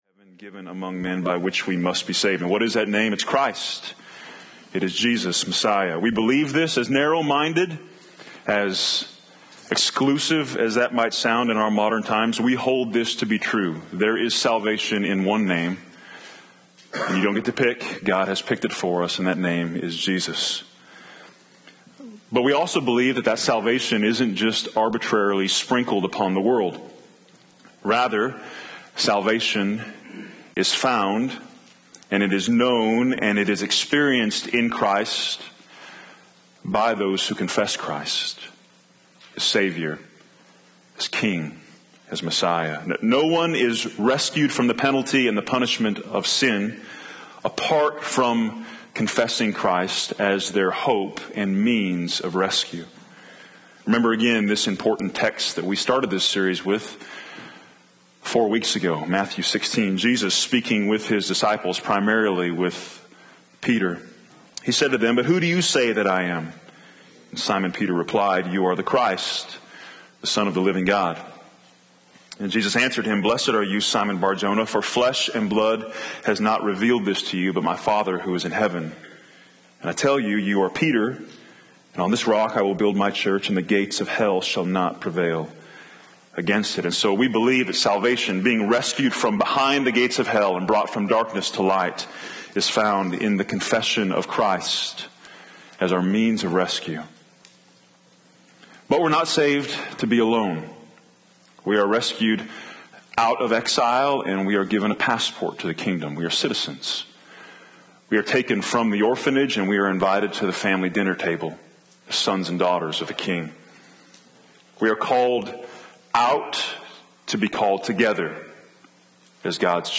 This series of sermons is focused on addressing this question, Why Church Planting? As we seek the Lord regarding the possibility of starting a new church family out of the KBC family, we want to answer this from a biblical perspective.